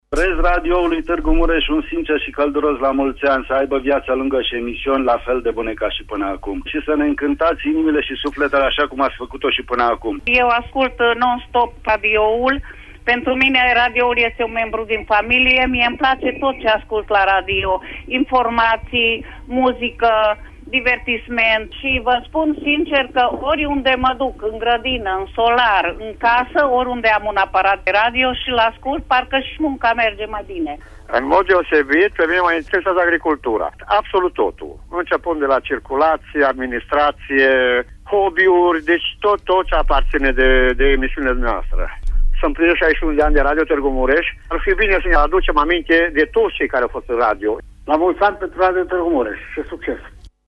1-mar-vox-aniversare.mp3